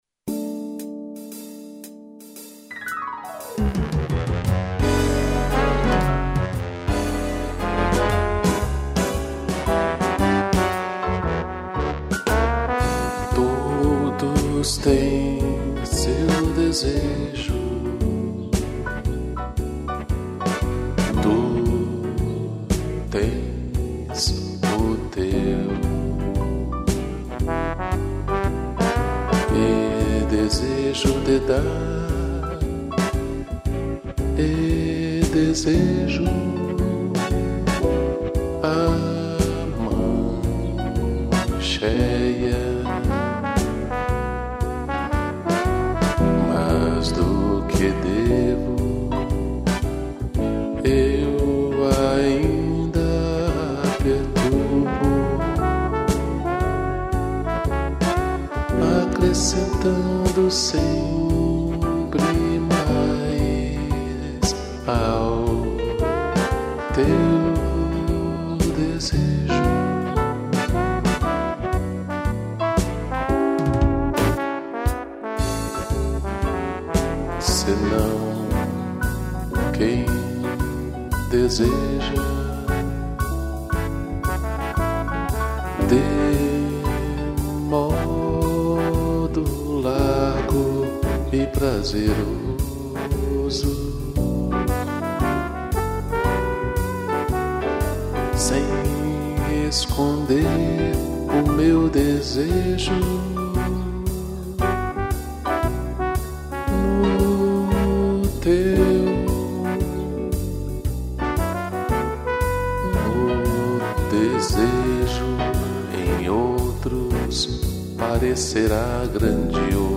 piano e trombone